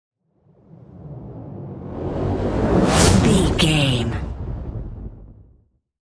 Whoosh to hit fire
Sound Effects
dark
intense
woosh to hit